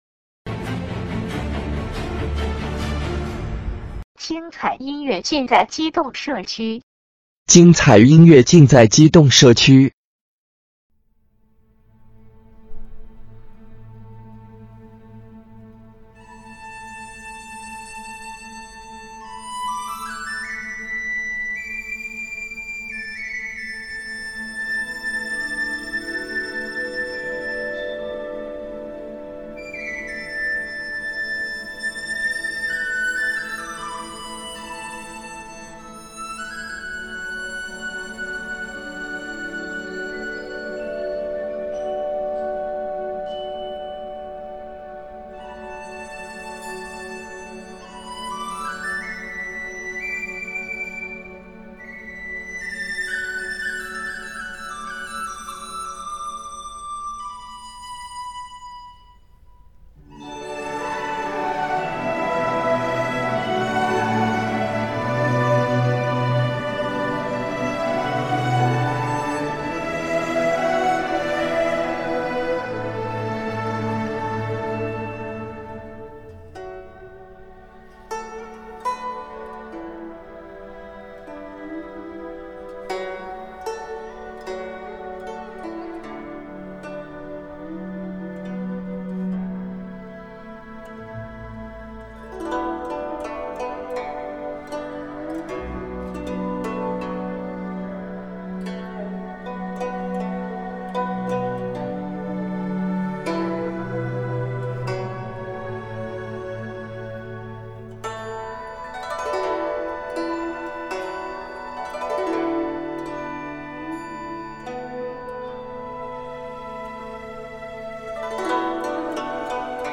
古筝与民族管弦乐